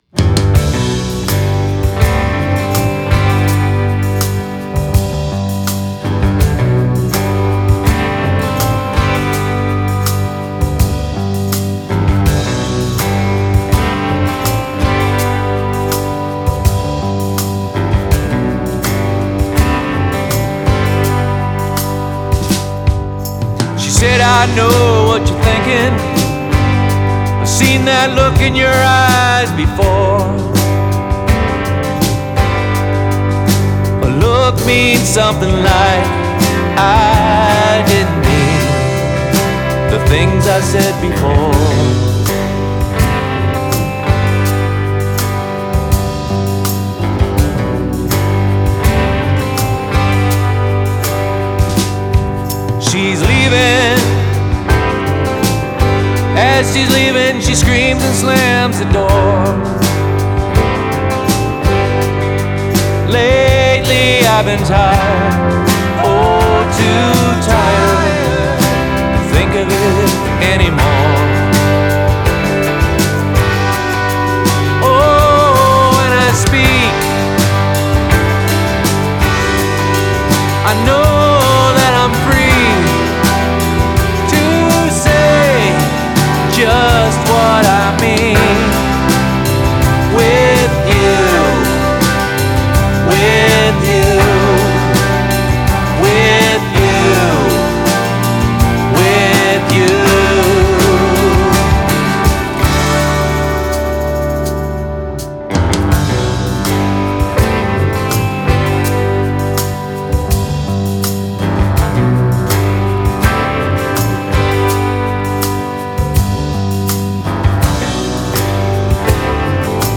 Hammond organ and Electric Piano
Particularly like the strings in this tune.